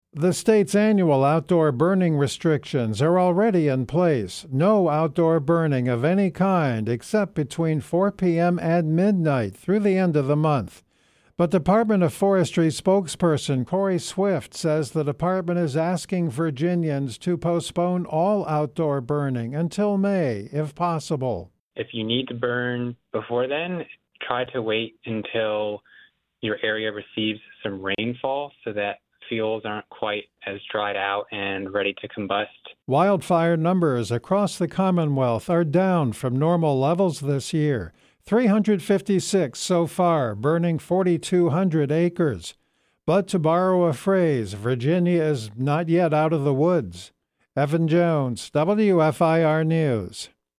The post State asks you to hold off outdoor burning until May first appeared on News/Talk 960-AM & FM-107.3 WFIR.